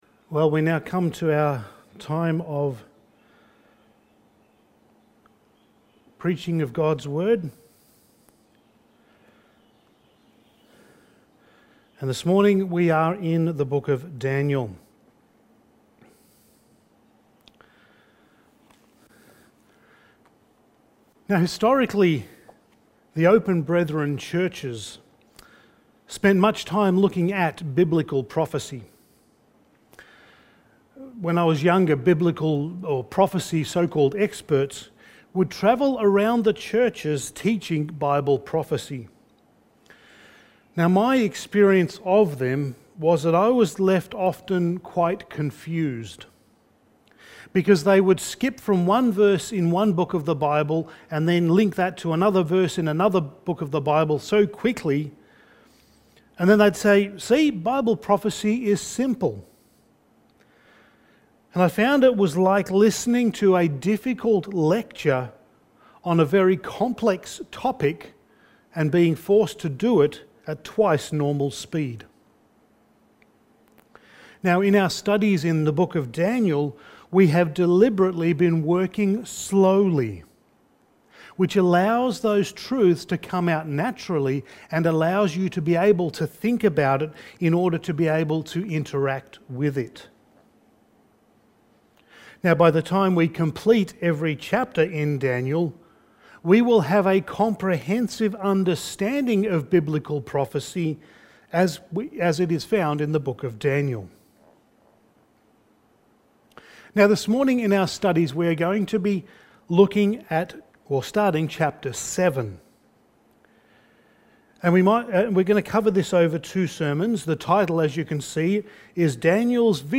Passage: Daniel 7:1-14 Service Type: Sunday Morning